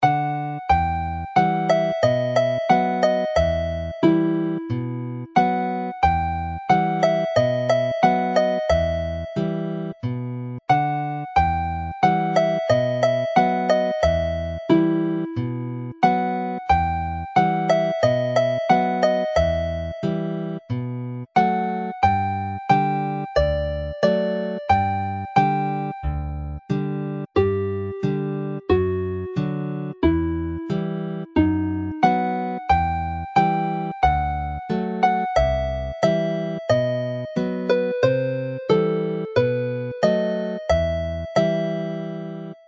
Play Slowly